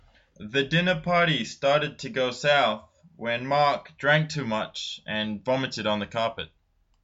英語ネイティブによる発音はこちらです。